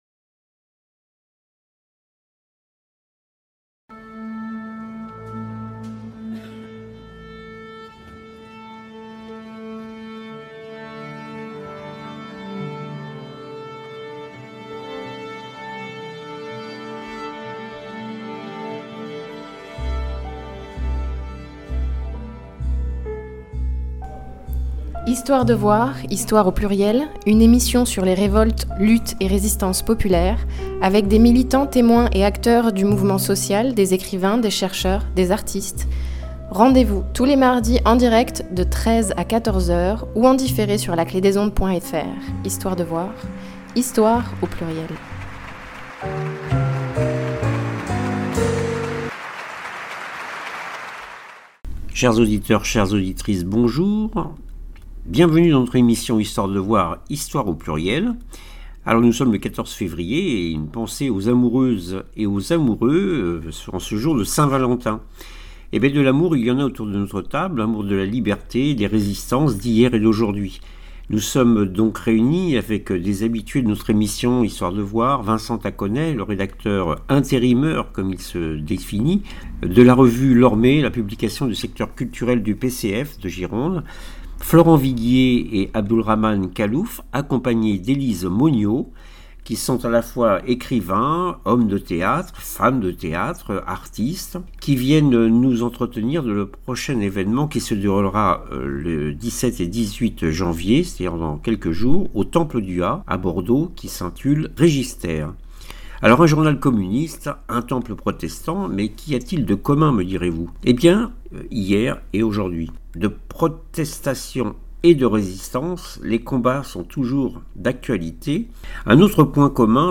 Nous sommes donc réunis avec des habitués de notre émission Histoire de voir.